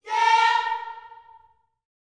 YEAH A#4B.wav